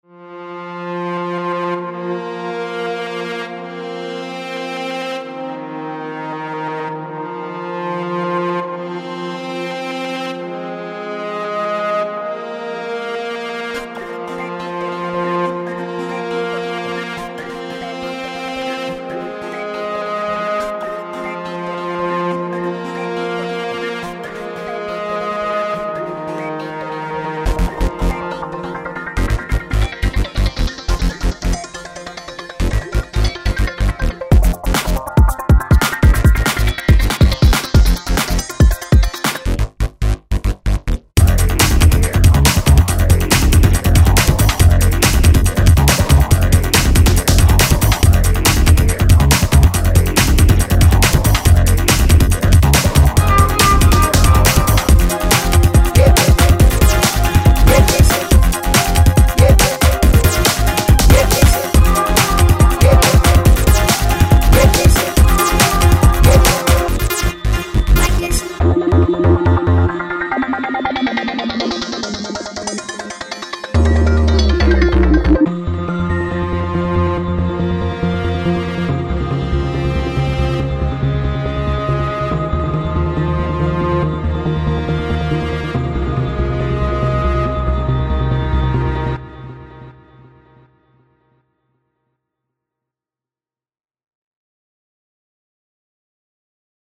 elektro/dub/down